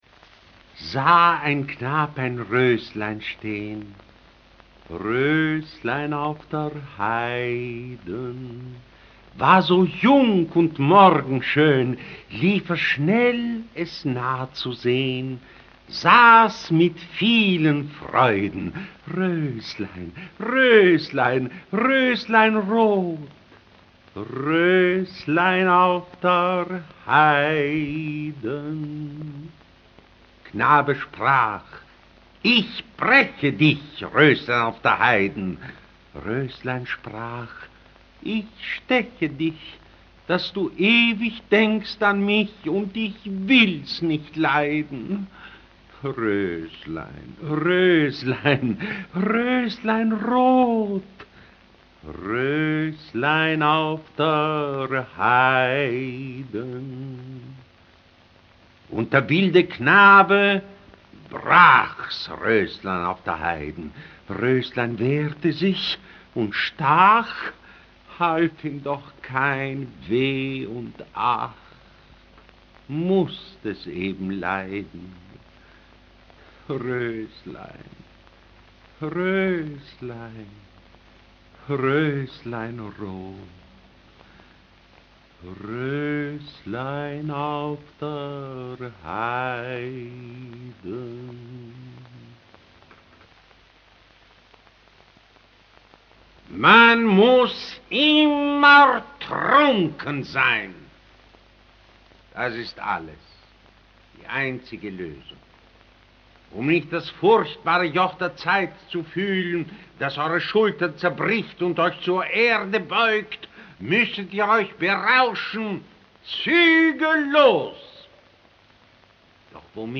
Für diese Gesellschaft sprach er auch Rezitationen: